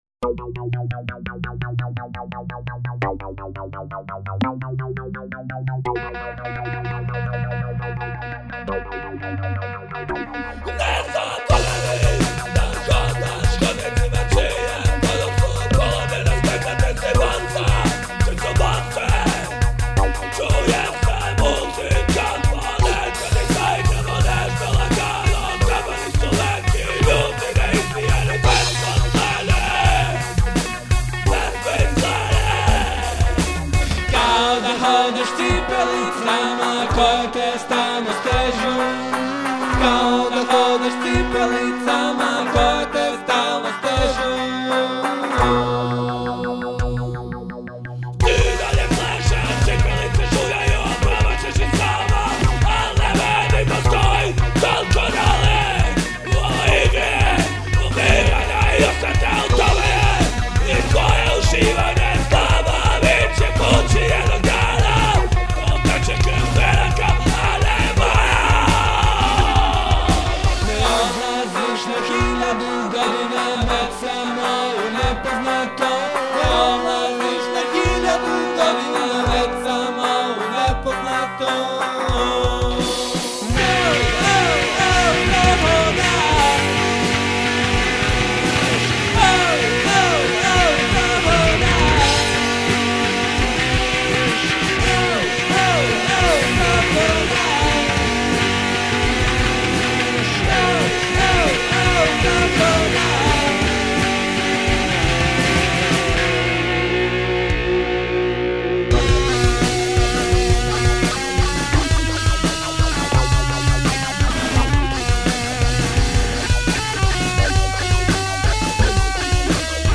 gitara, vokal
bass, vokal
bubnjevi